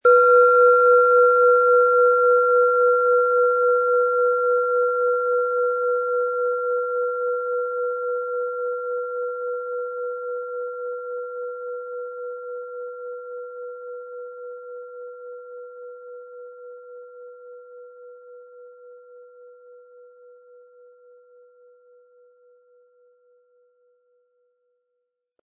Planetenschale® Potentiale erkennen & Weibliche Kraft leben mit Lilith, Ø 11,1 cm, 180-260 Gramm inkl. Klöppel
Planetenton 1
Die Schale, mit Lilith, ist eine in uralter Tradition von Hand getriebene Planetenklangschale.
Um den Original-Klang genau dieser Schale zu hören, lassen Sie bitte den hinterlegten Sound abspielen.